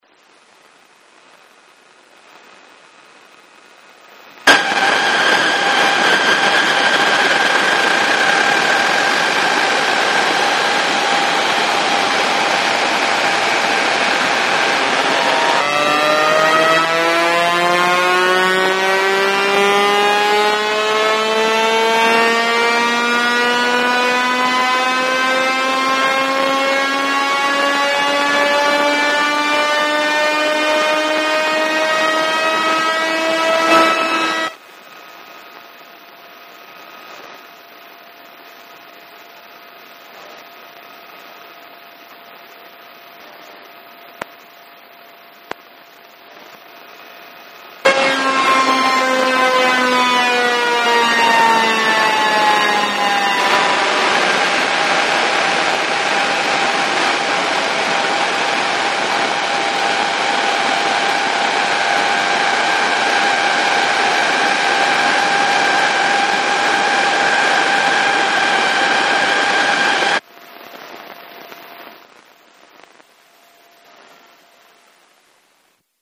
7000�nVVVF(AM���W�I�Ŏ��^) ��Đ�(449KB)
kintetsu7000VVVF.mp3